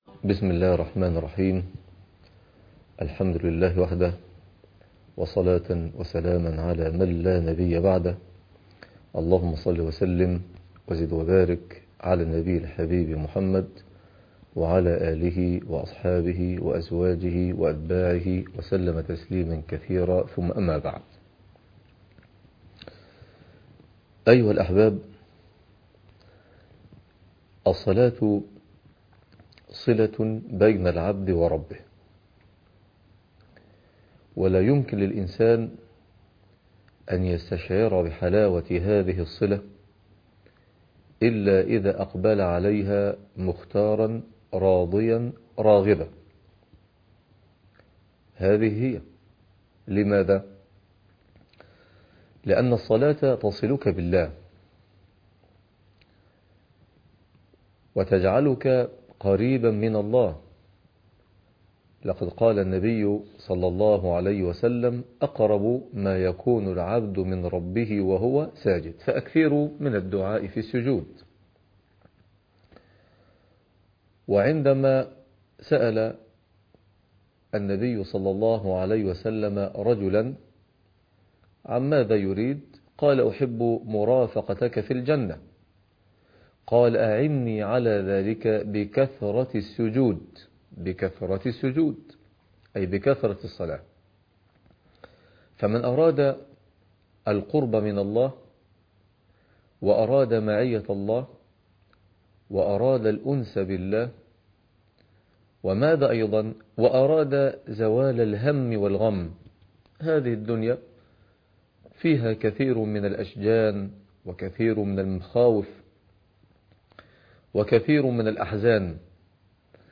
عنوان المادة 04 - الدرس الرابع من برنامج محاسن الإسلام - القرآن تاريخ التحميل الثلاثاء 10 يناير 2023 مـ حجم المادة 9.22 ميجا بايت عدد الزيارات 251 زيارة عدد مرات الحفظ 119 مرة إستماع المادة حفظ المادة اضف تعليقك أرسل لصديق